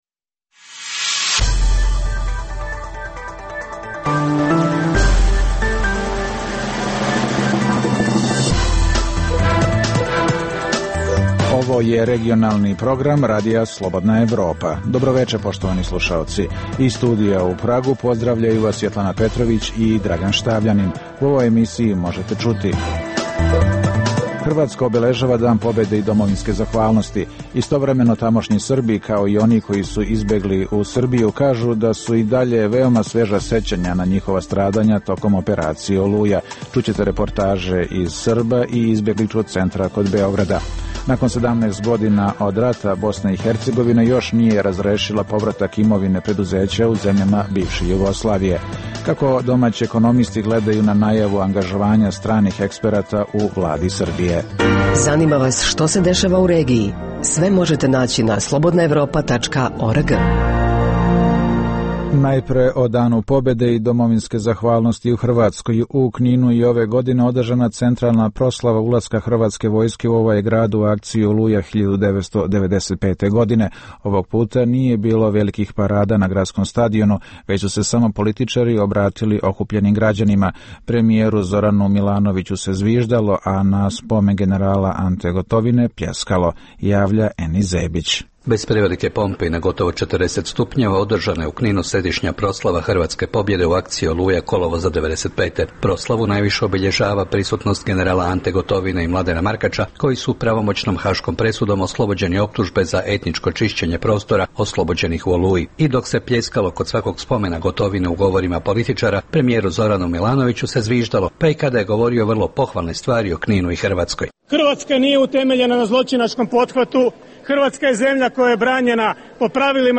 Čućete reportažu iz Srba i izbegličkog centra kod Beograda.